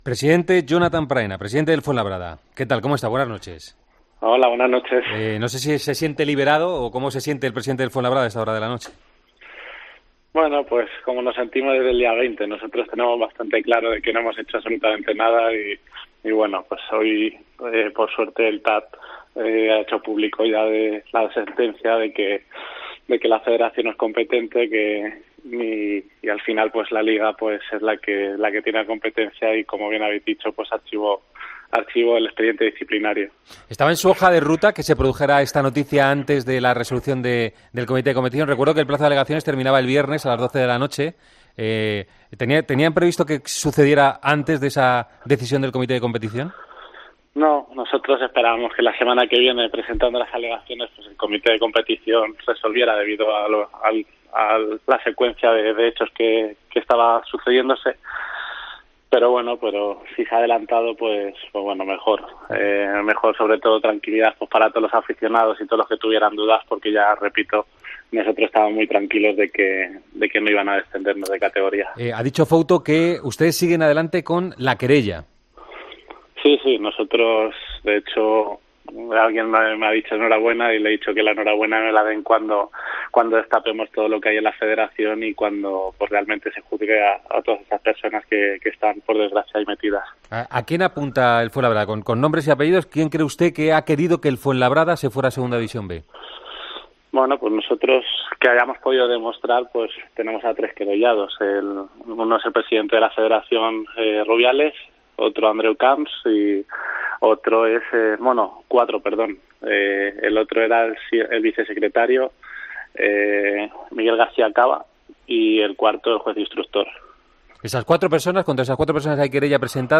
ha visitado El Partidazo de COPE tras la decisión del TAD de mantener al equipo fuenlabreño en...